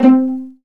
PIZZ VLN C3.wav